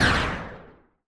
arcaneimpact2.wav